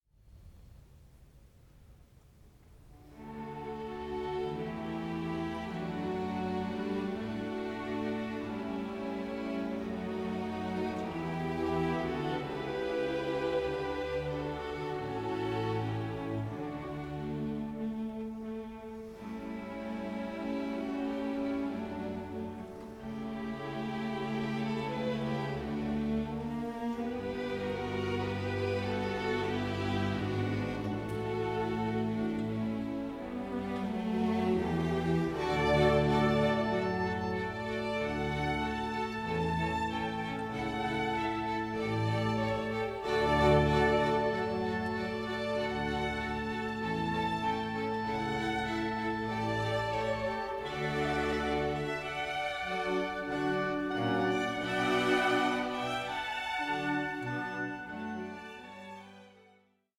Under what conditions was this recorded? (DSD DSF) Stereo & Surround 20,99 Select